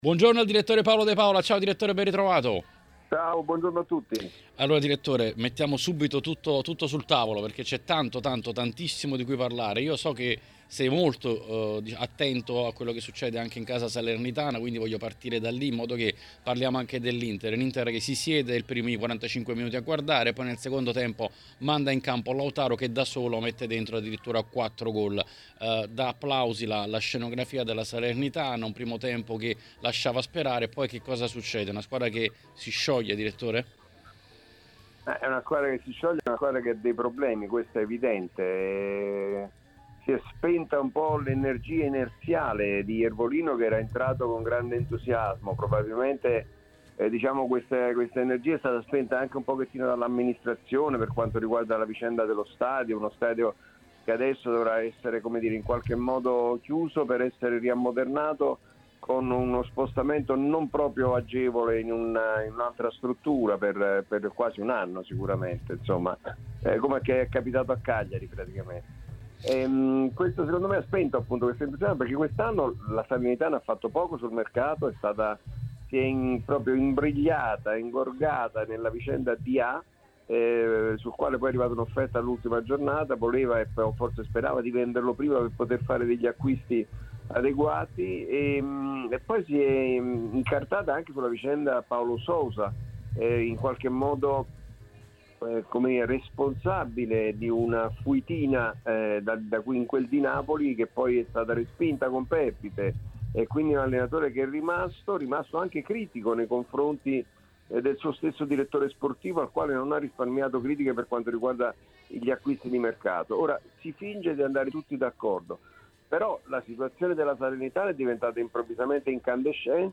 Durante l'appuntamento odierno con L’Editoriale è intervenuto sulle frequenze di TMW Radio